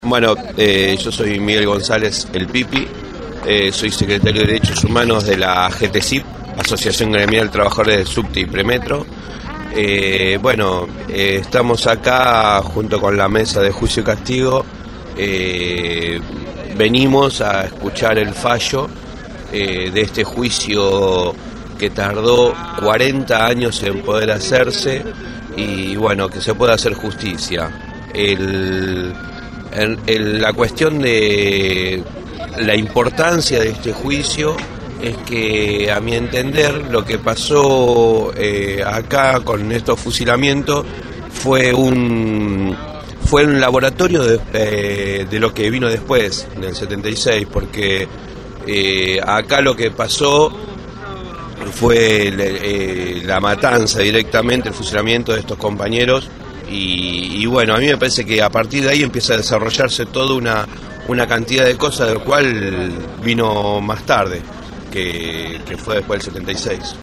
La sentencia se conoció en el centro Cultural Municipal «José Hernández», de Rawson.
Los enviados especiales de Radio Gráfica recopilaron los testimonios de los mismos.